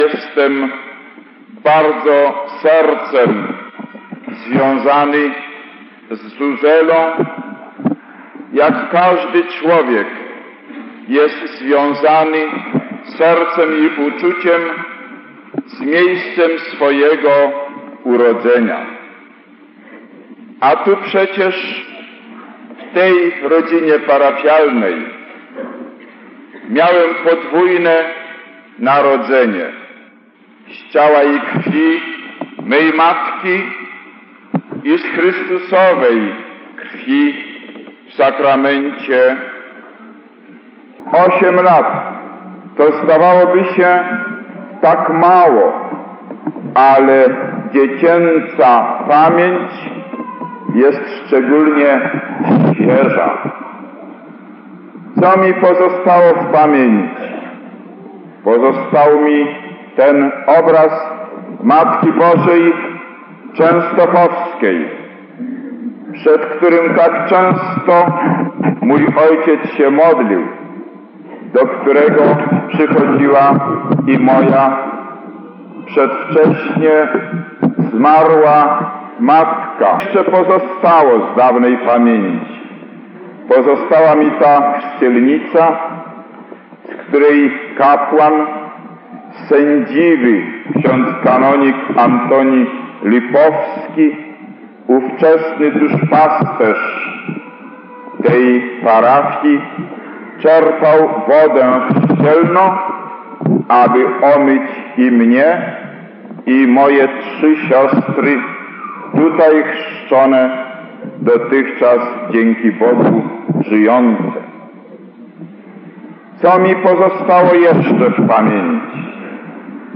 Kardynał Wyszyński tak wspominał miejsce swojego urodzenia podczas kazania w Zuzeli w dniu 13 maja 1971 roku odsłuchaj kazanie (kardynał)
kazanie_zuzela.mp3